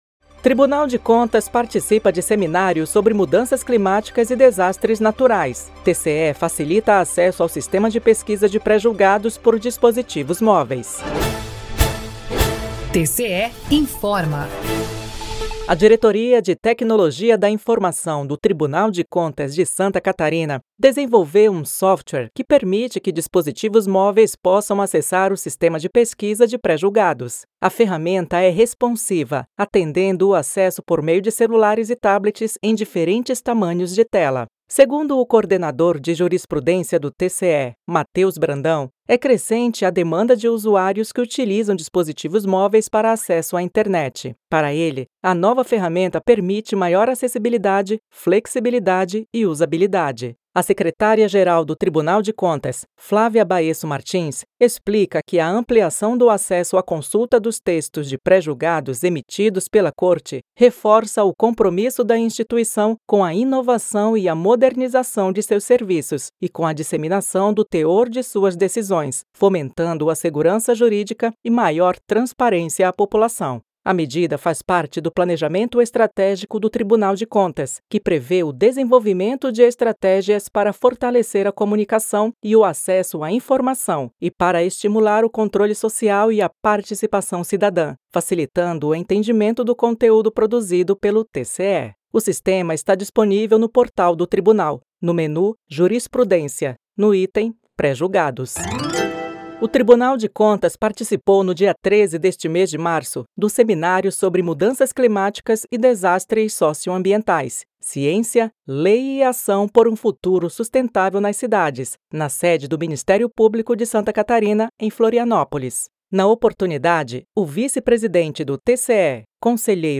VINHETA TCE INFORMA
SINAL SONORO
VINHETA TCE INFORMOU